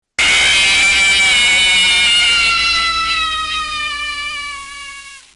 Fnaf-1-Full-Jumpscare-Sound.m4a
KFesD1uSCcN_Fnaf-1-Full-Jumpscare-Sound.m4a